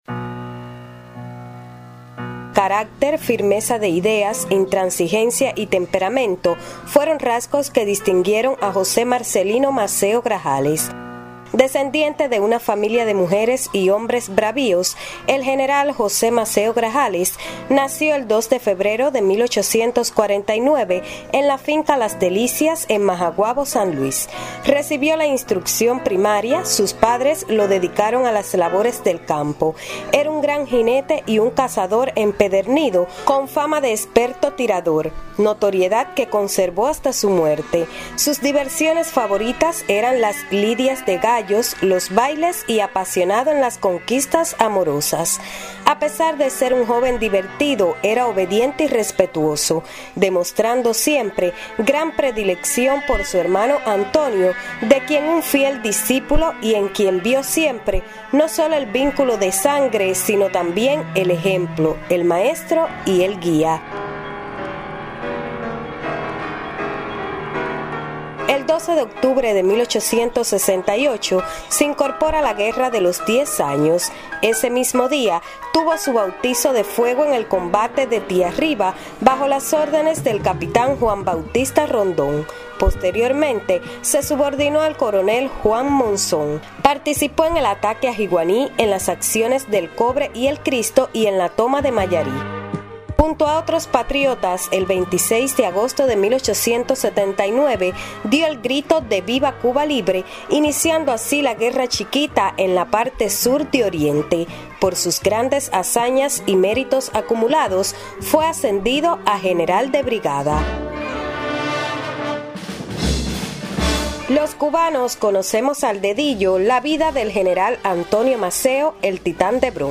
Jose-Maceo-Grajales-Un-sanluisero-de-gran-impetu-y-patriotismo-mambi-Cronica.mp3